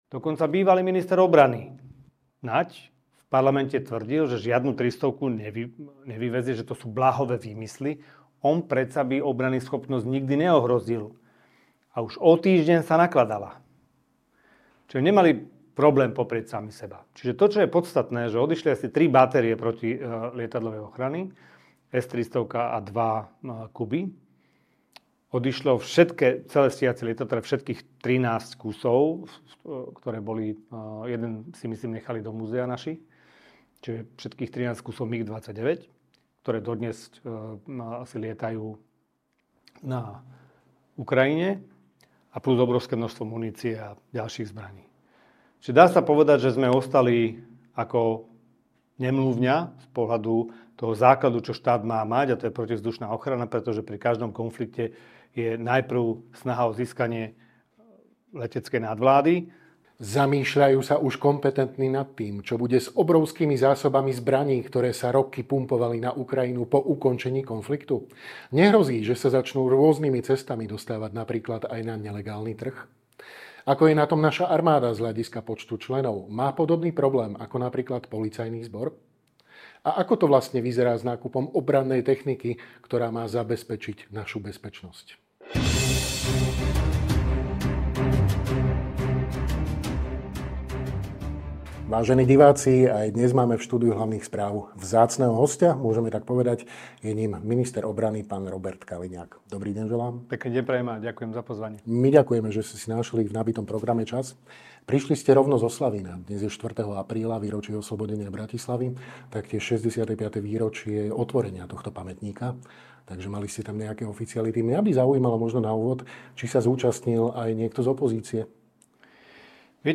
Rozprávali sme sa s ministrom obrany a podpredsedom vlády SR, JUDr. Robertom Kaliňákom.